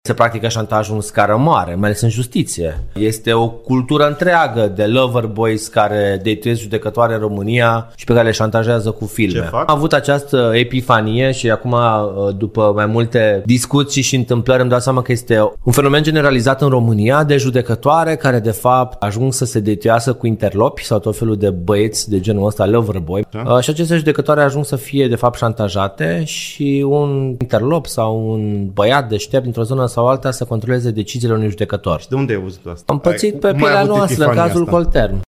Declarațiile au fost făcute de Ruben Lațcău, într-un podcast al colegului său de partid, Cristian Ghinea. O astfel de judecătoare, susține el s-a pronunțat în dosarul Colterm.